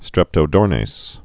(strĕptō-dôrnās, -nāz)